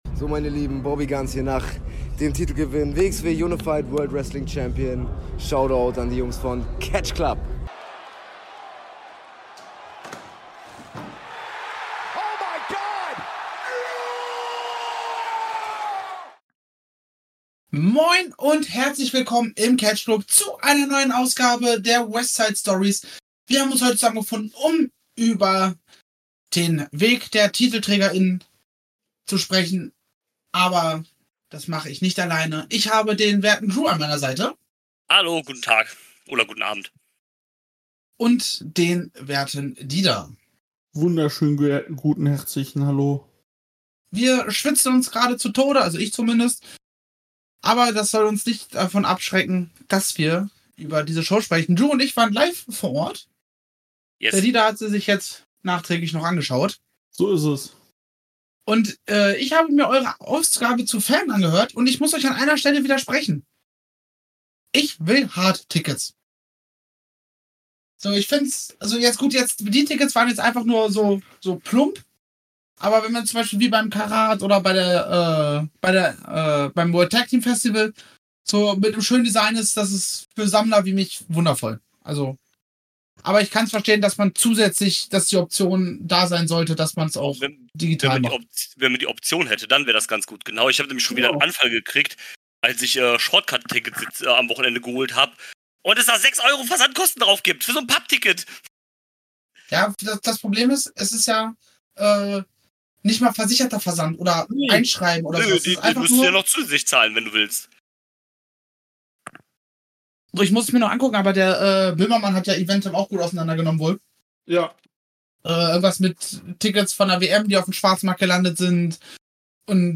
Zu dritt besprechen wir die Show und die Auswirkungen auf die Zukunft.